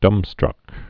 (dŭmstrŭk)